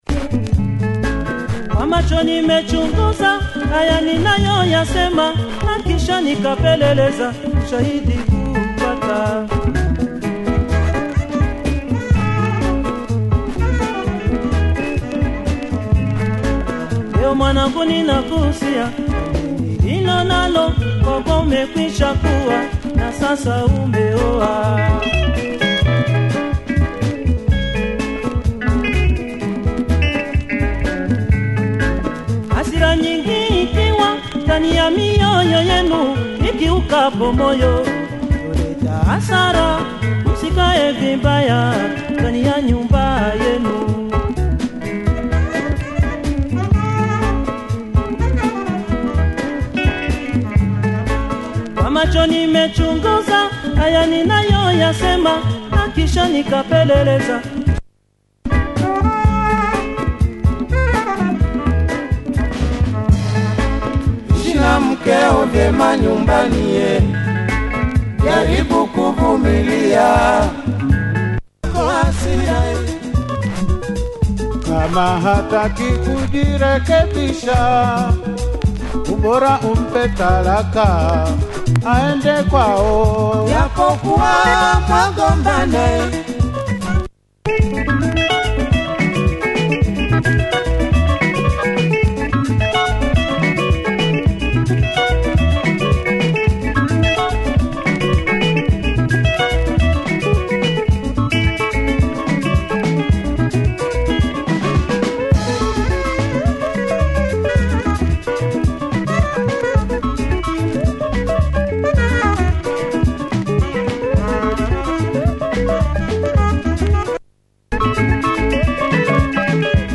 rumba track with great sax